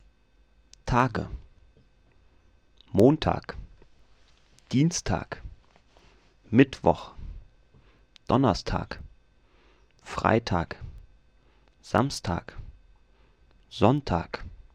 German_Vocabulary_-_Days.ogg.mp3